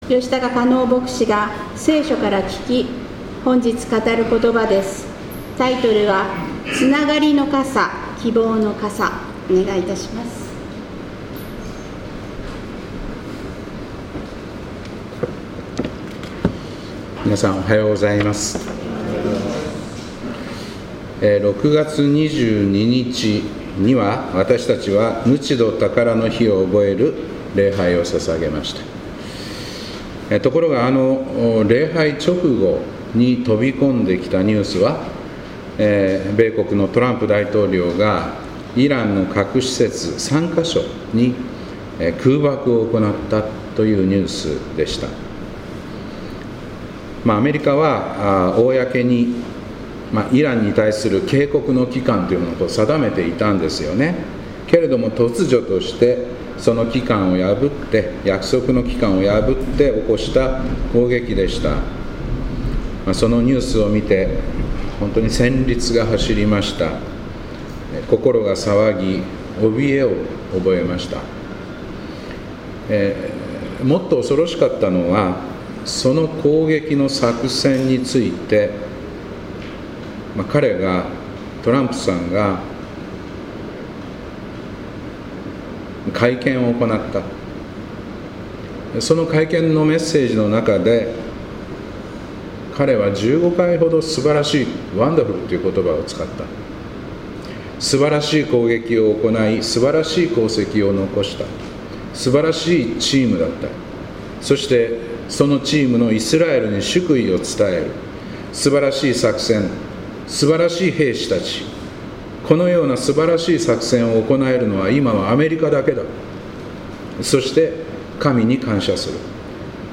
2025年8月10日平和祈念礼拝「つながりの傘・希望の傘」